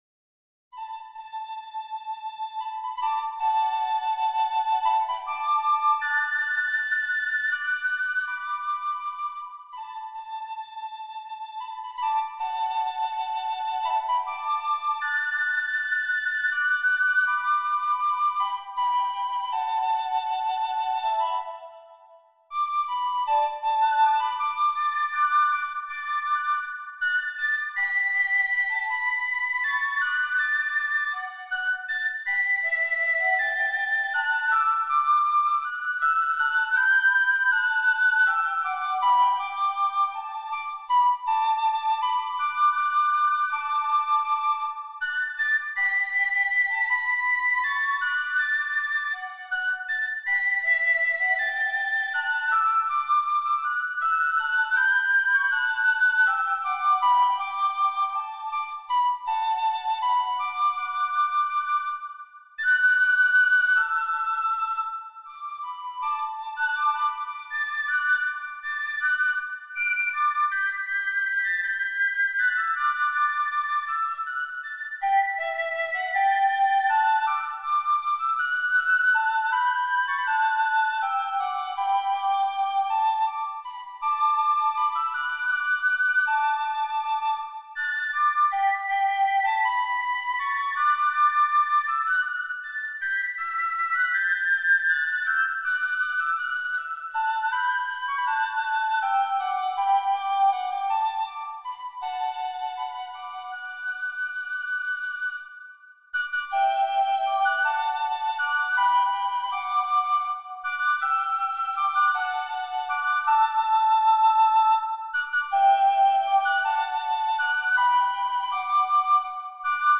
Piccolo Duos on Irish Themes